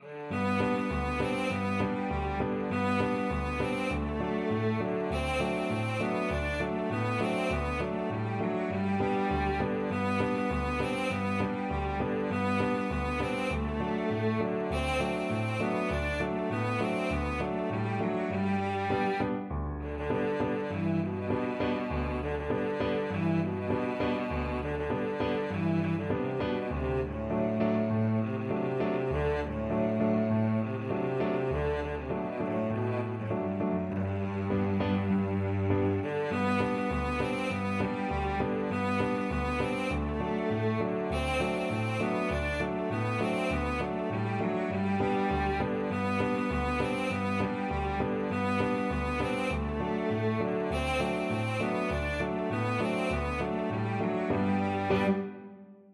Allegretto
2/4 (View more 2/4 Music)